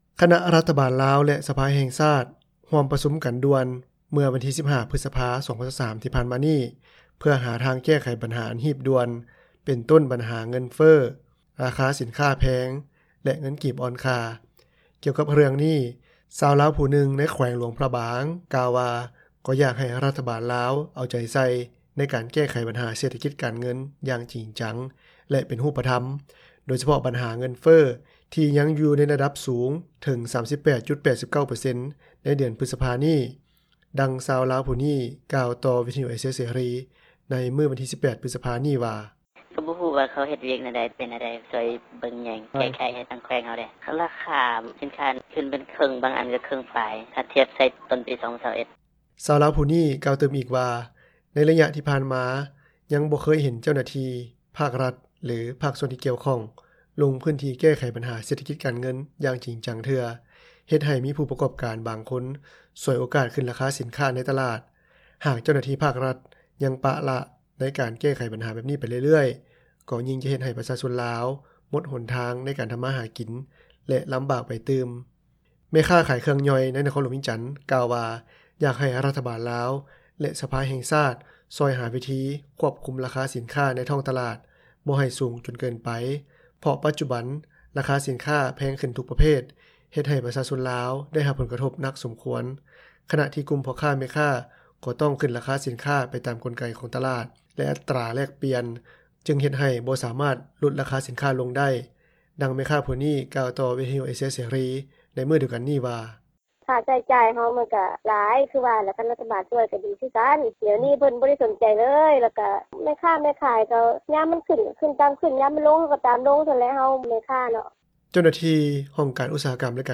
ດັ່ງແມ່ຄ້າຜູ້ນີ້ ກ່າວຕໍ່ວິທຍຸ ເອເຊັຽ ເສຣີ ໃນມື້ດຽວກັນນີ້ວ່າ:
ດັ່ງເຈົ້າໜ້າທີ່ຜູ້ນີ້ ກ່າວຕໍ່ວິທຍຸ ເອເຊັຽ ເສຣີ ໃນມື້ດຽວກັນນີ້ວ່າ: